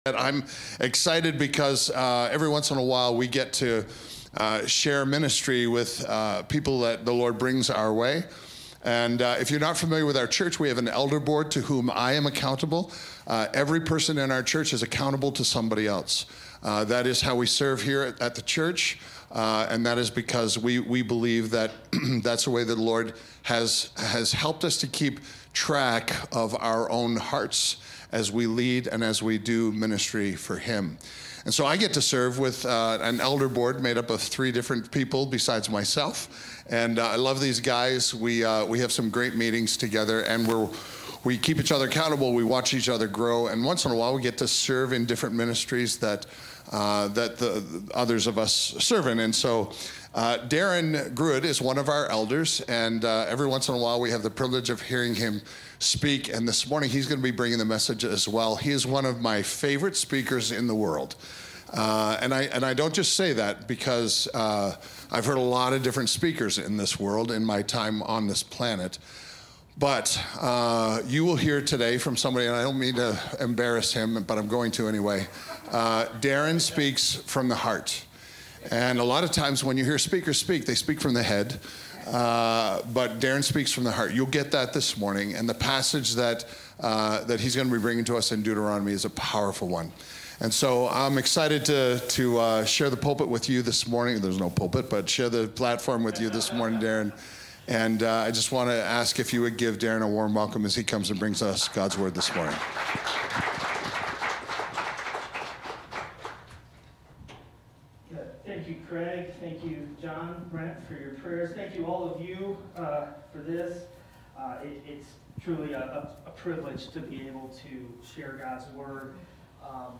This sermon explores how a possession is something you own, but a treasured possession is something you value greatly. God calls his people a treasured possession.